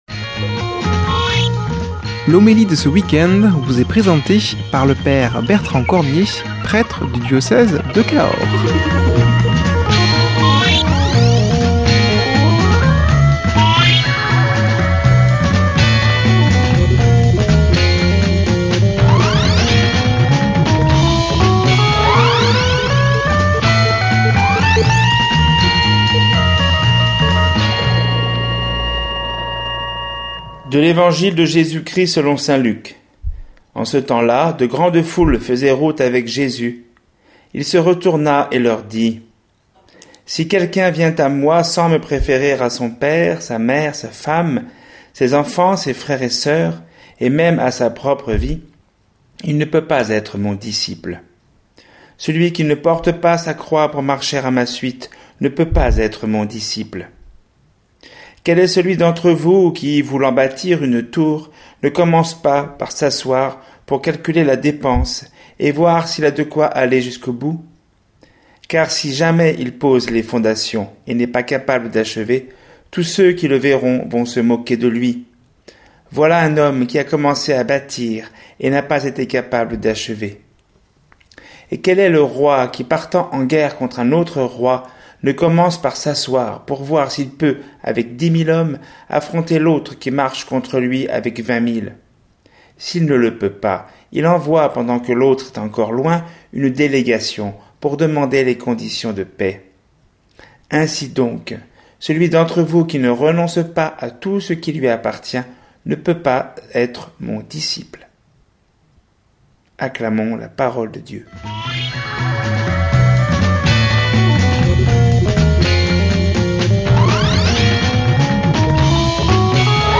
Homélie
[ Rediffusion ]